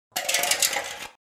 BottleRemoveFromInventory.ogg